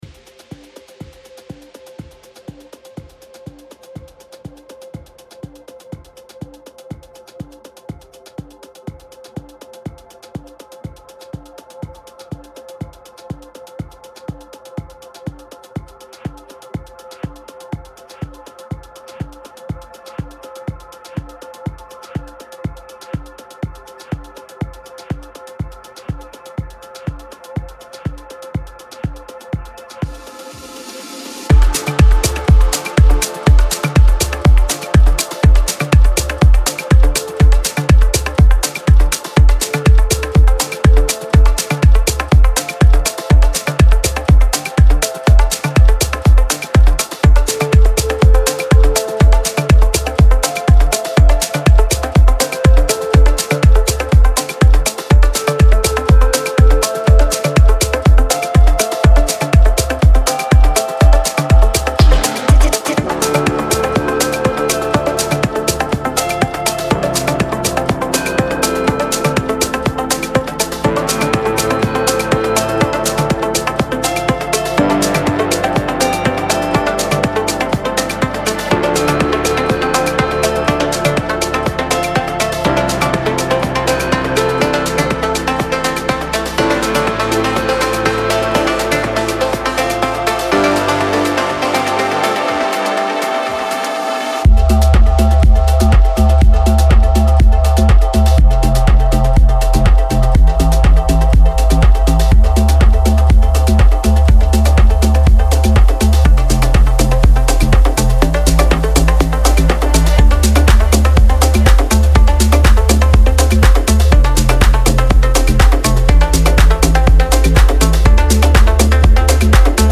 Melodic House and Techno